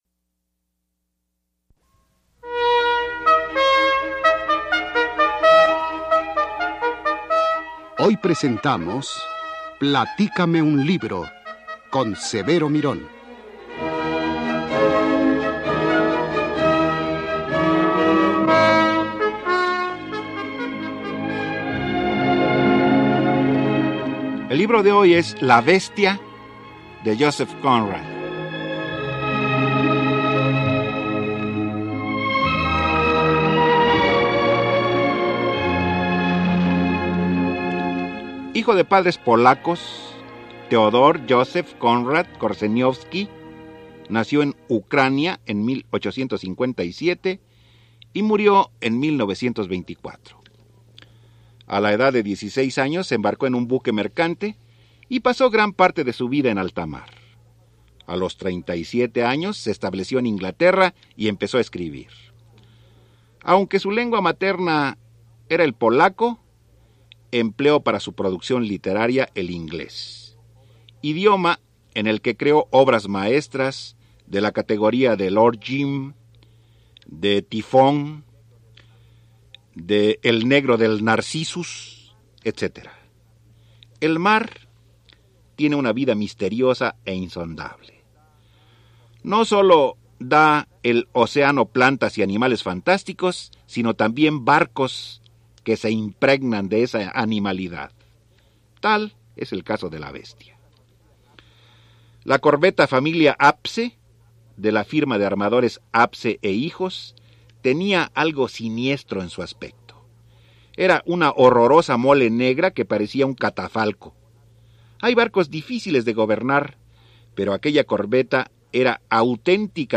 Escucha “La bestia”, relato